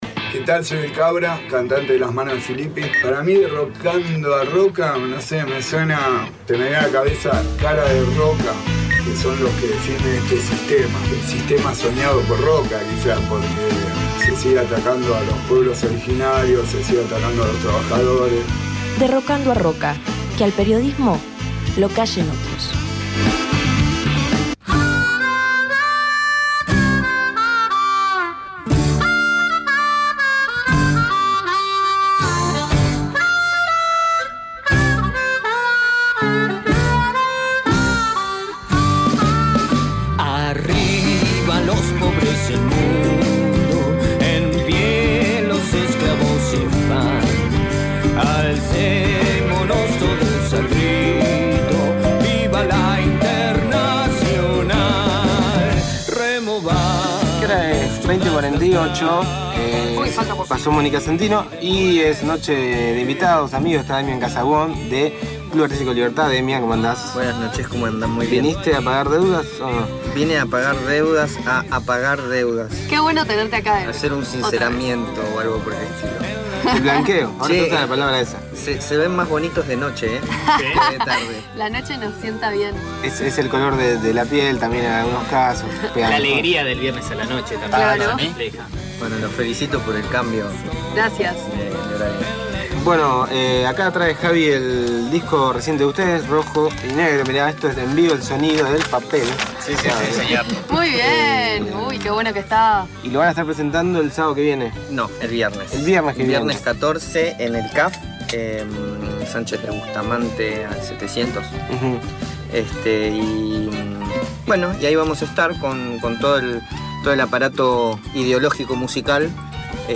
Entrevista
Como perlita, agarró la viola y nos dejó una sorpresa especial para el programa.